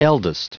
Prononciation du mot eldest en anglais (fichier audio)
Prononciation du mot : eldest